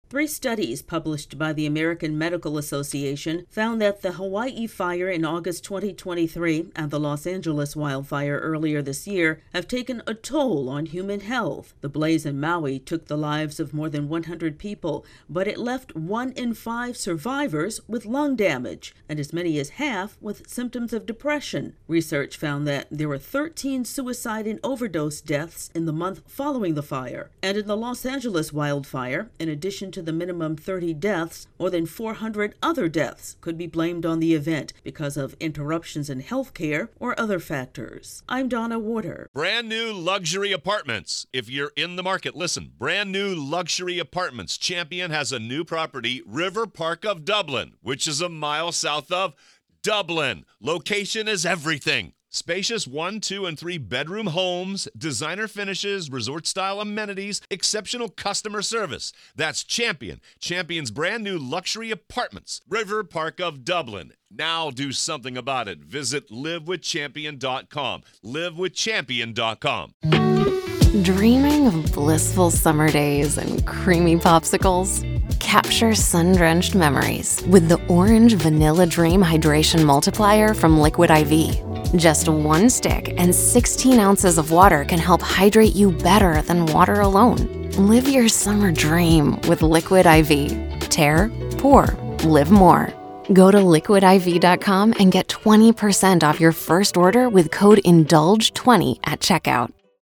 Three new studies highlight the cost to human health from the wildfires in Maui and Los Angeles. AP correspondent